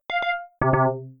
Correct / Incorrect Tones
abstract correct digital ding electro electronic future incorrect sound effect free sound royalty free Sound Effects